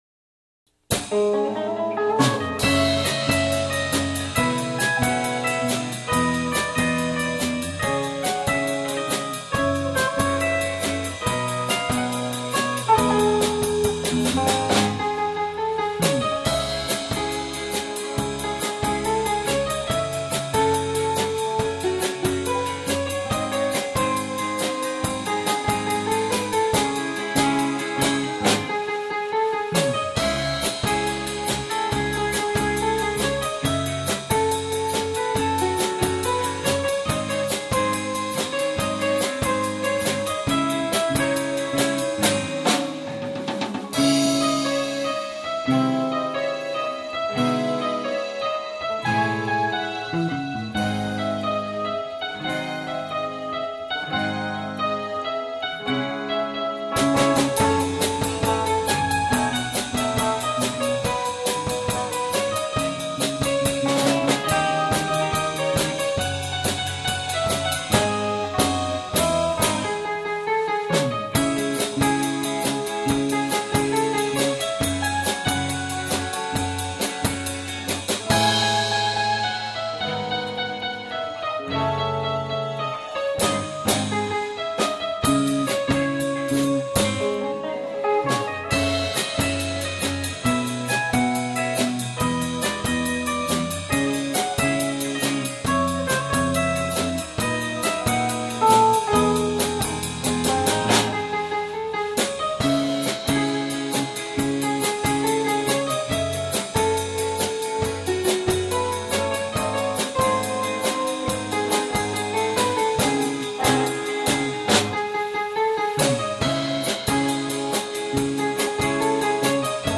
2019 X’mas Live